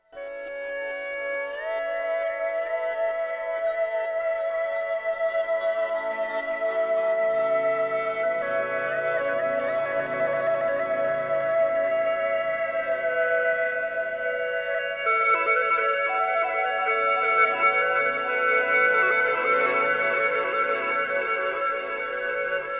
CHINESE AT.wav